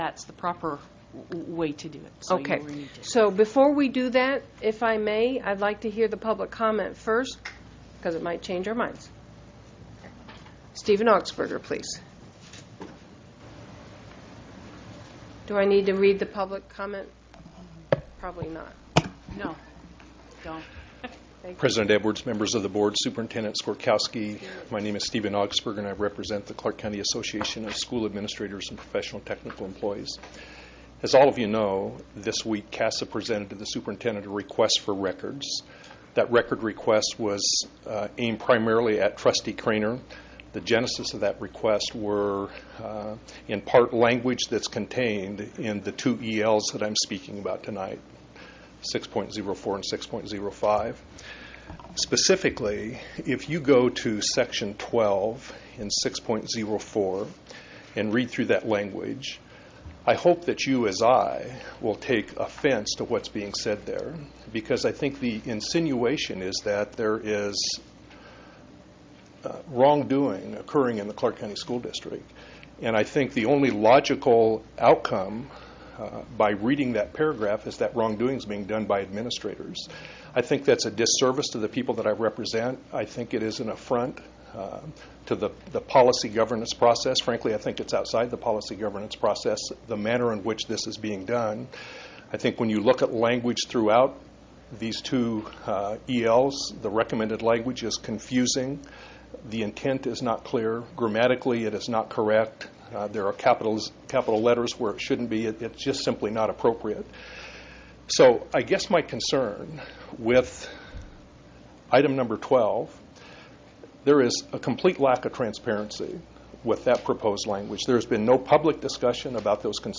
remarks-before-ccsd-trustees